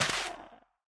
Minecraft Version Minecraft Version 1.21.5 Latest Release | Latest Snapshot 1.21.5 / assets / minecraft / sounds / block / soul_sand / break9.ogg Compare With Compare With Latest Release | Latest Snapshot